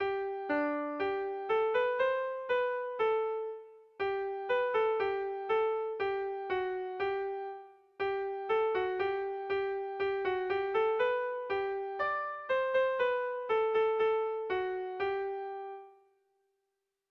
Air de bertsos - Voir fiche   Pour savoir plus sur cette section
Bizkaia < Euskal Herria
8A / 8B / 10A / 8B